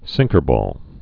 (sĭngkər-bôl)